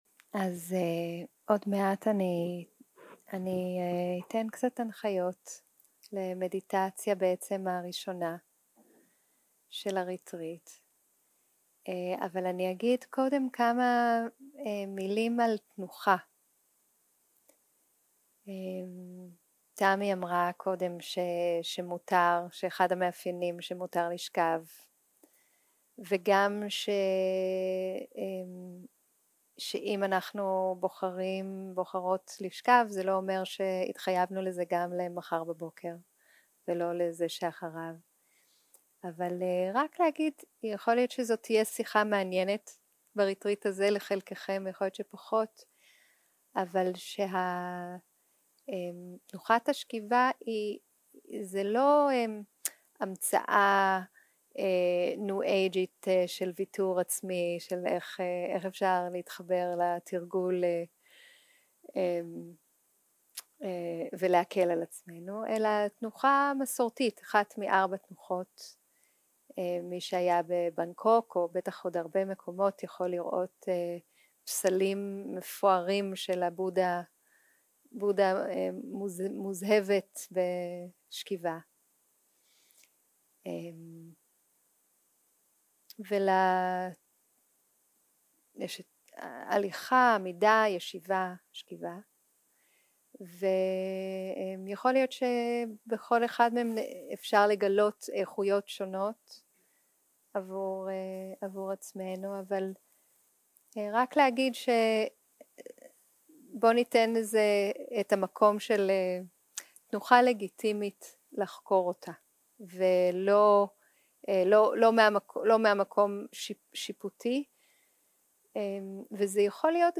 יום 1 - הקלטה 1 - ערב - הנחיות למדיטציה
שיחת הנחיות למדיטציה